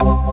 ORGAN-36.wav